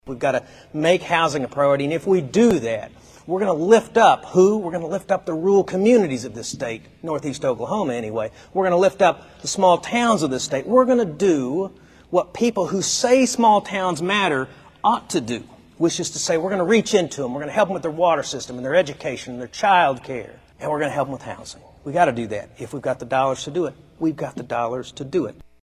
The Principal Chief of the Cherokee Nation spoke to the Nowata Chamber of Commerce during their November meeting.